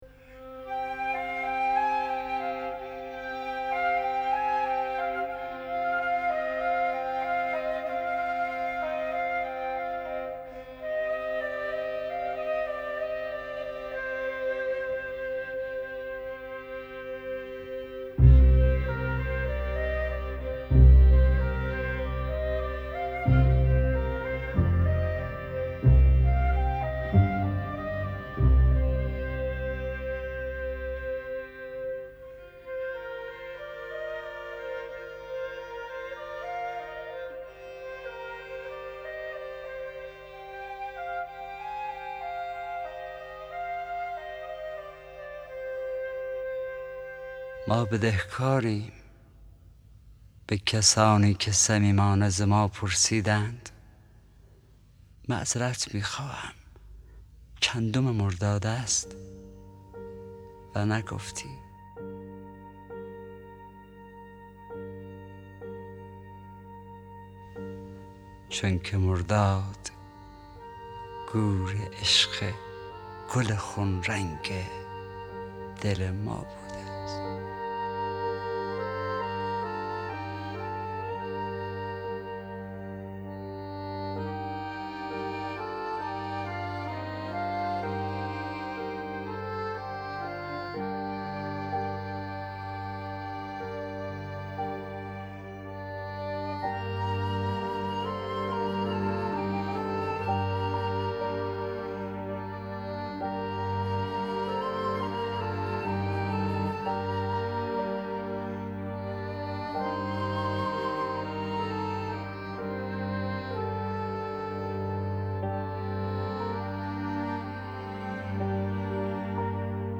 دانلود دکلمه ما بدهکاریم با صدای حسین پناهی با متن دکلمه
گوینده :   [حسین پناهی]